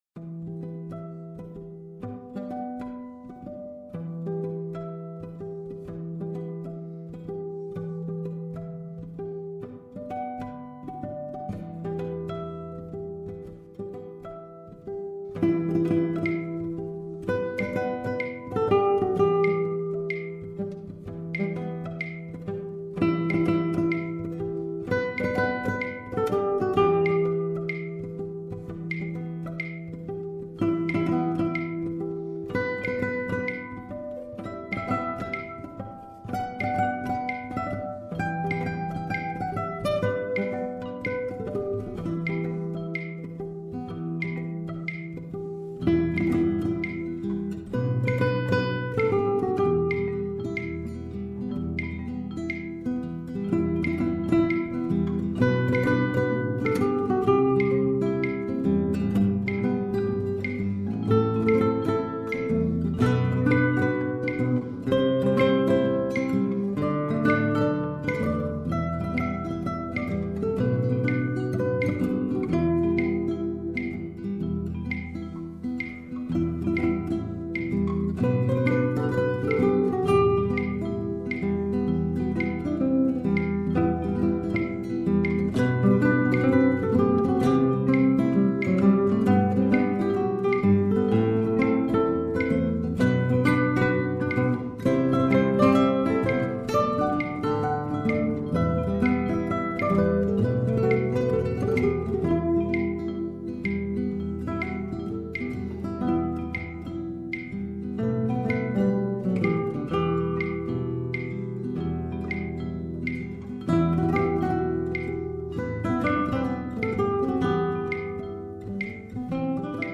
solo gitarre